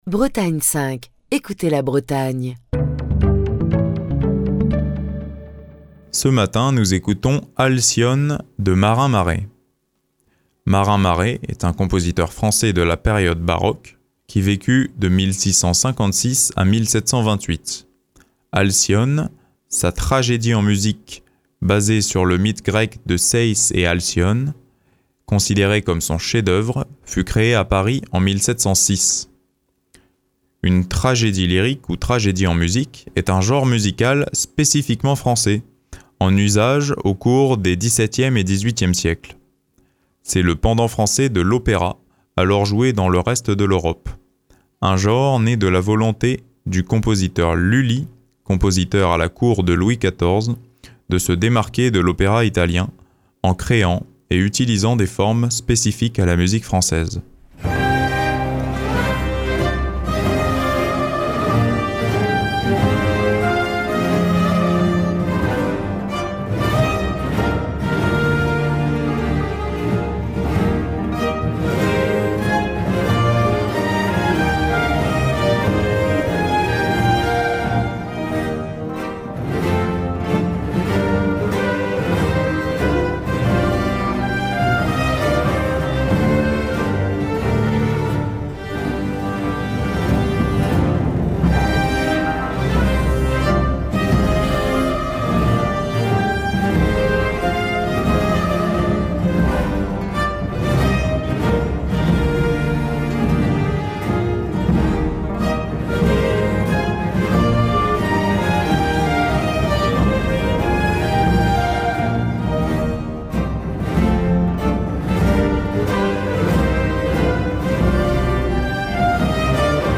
une tragédie en musique